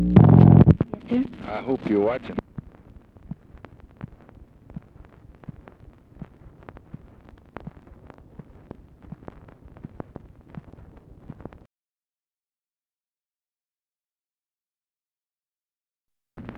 LBJ SAYS "I HOPE YOU'RE WATCHING" BEFORE RECORDING ENDS
Conversation with LUCI JOHNSON NUGENT?
Secret White House Tapes | Lyndon B. Johnson Presidency Conversation with LUCI JOHNSON NUGENT?